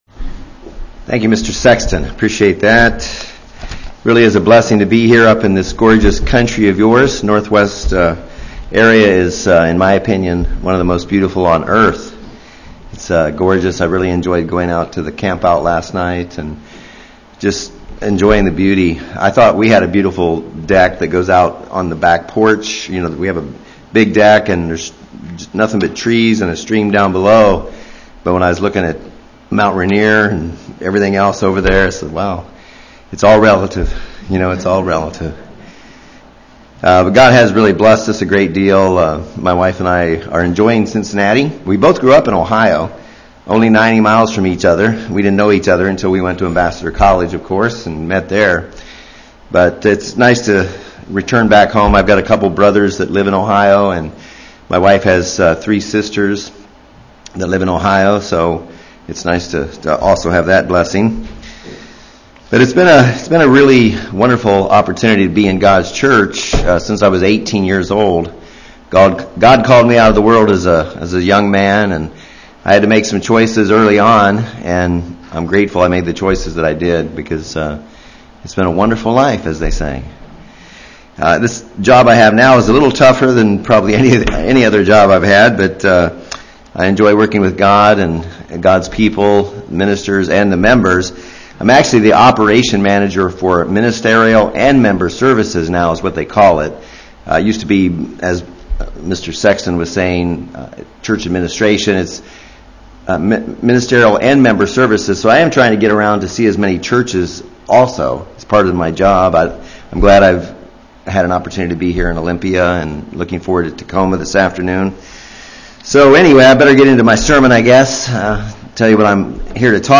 A "how to" sermon utilizing basic spiritual principles in powerful ways to overcome sin.